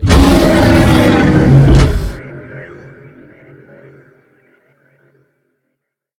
CosmicRageSounds / ogg / general / combat / enemy / droid / bigatt3.ogg